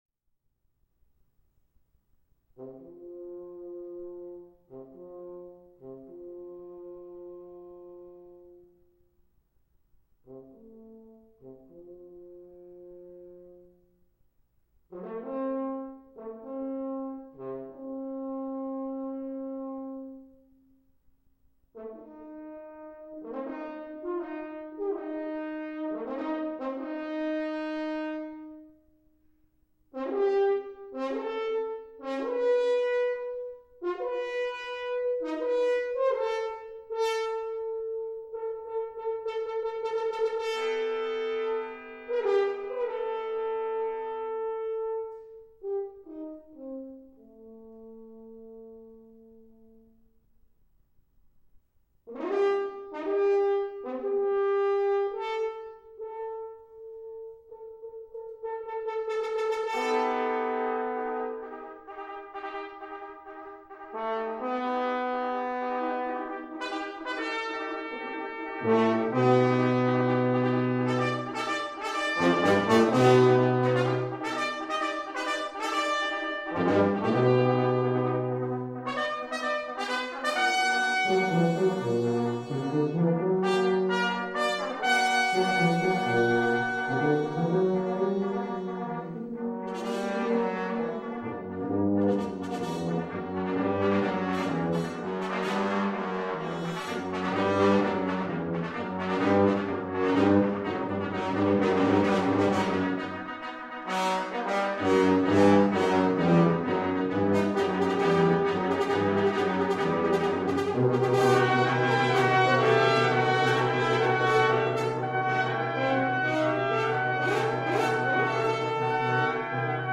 This is a single-movement work in a genre that is sort of starting to define a large part of my output – let’s call it a “psychological tone poem”.
quintet edit.mp3